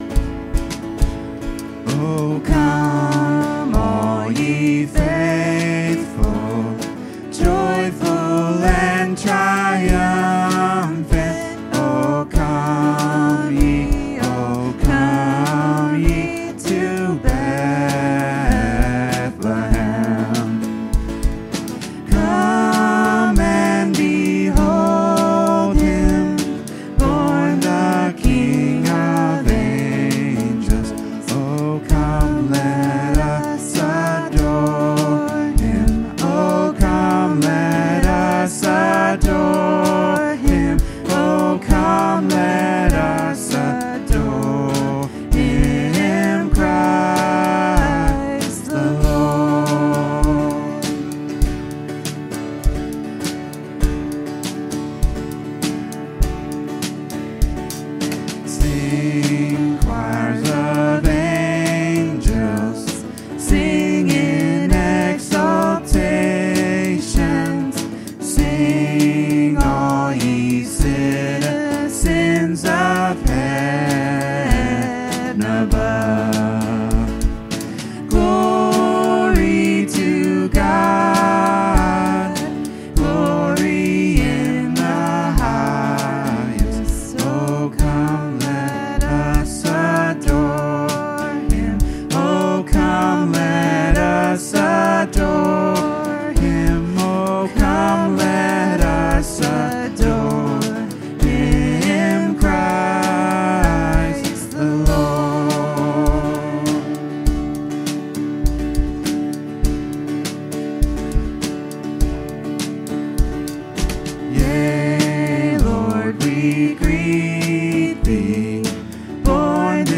Worship 2025-12-21